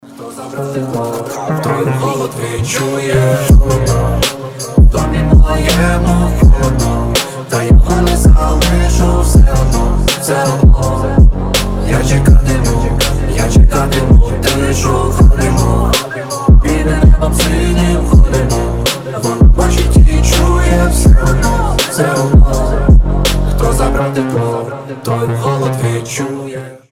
Club house
Женский голос
Поп Танцевальные